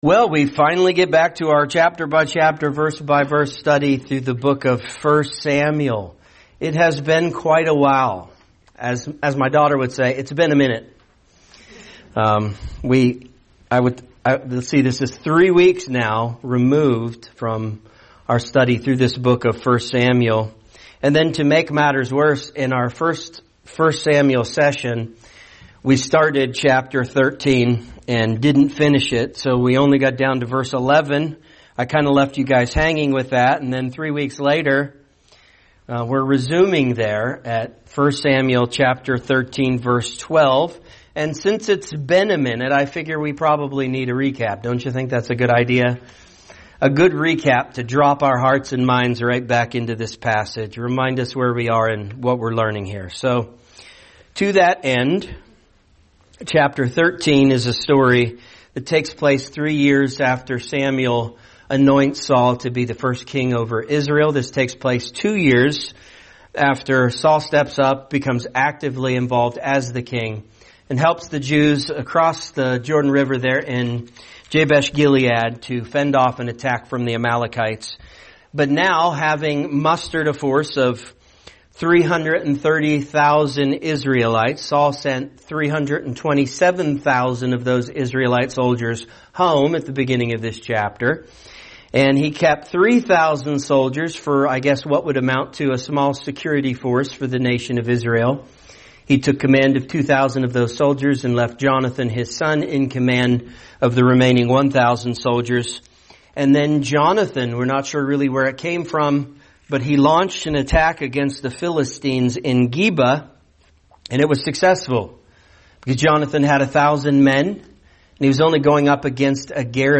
A message from the topics "The Book of 1 Samuel."